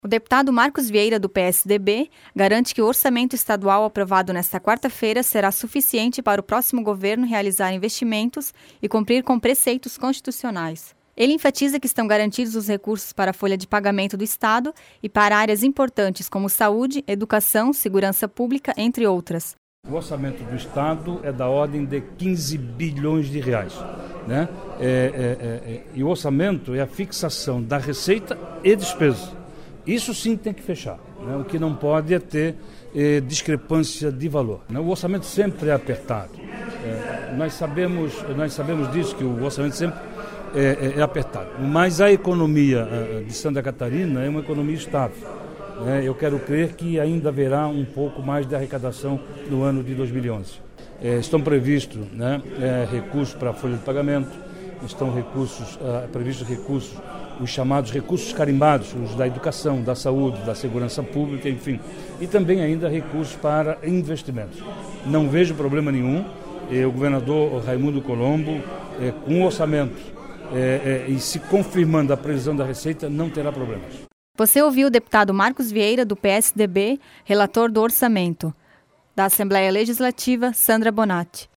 Notícias